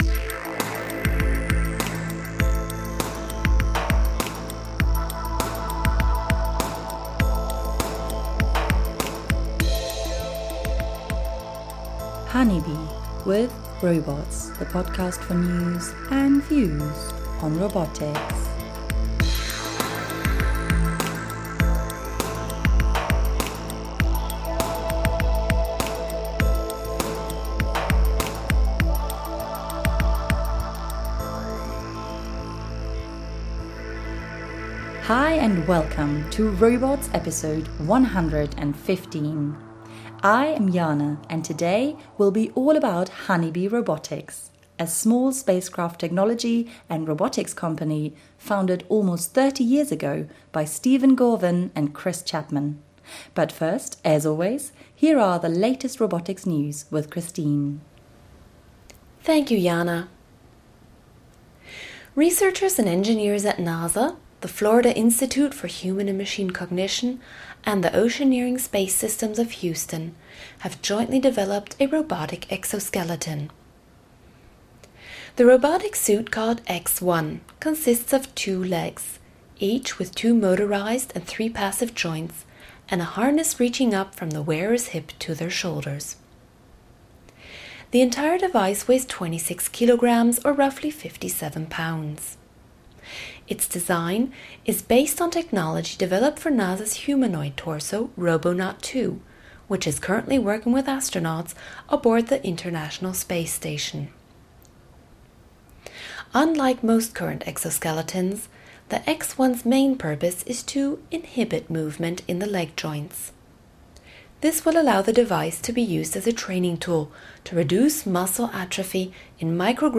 The interview, which was recorded before the landing of Curiosity on Mars, gives us all the details about the equipment they built for the Mars Science Laboratory, including the Sample Manipulation System (SMS) and the Dust Removal Tool (DRT) which will enable the mission to explore and analyze the Martian environment.